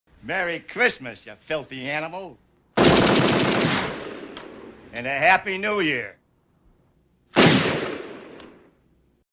Merry Christmas Ya Filthy Animal (with Gunshots) (Home Alone)
MerryChristmasYaFilthyAnimal-WithGunshots.mp3